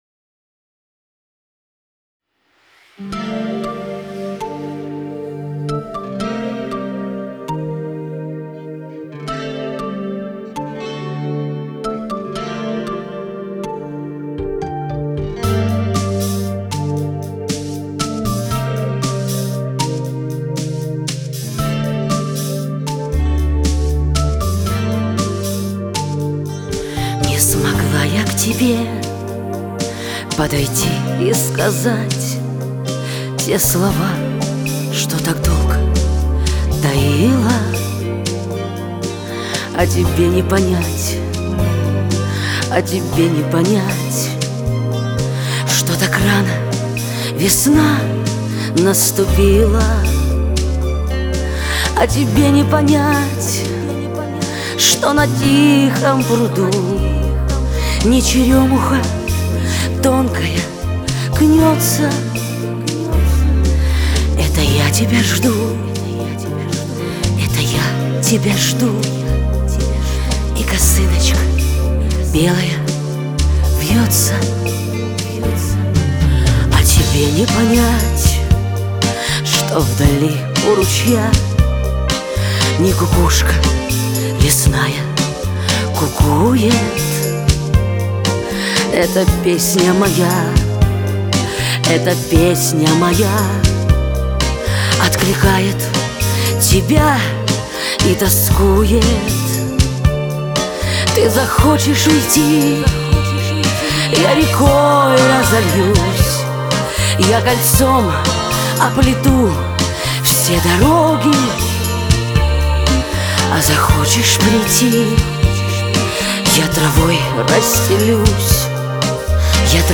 шансонный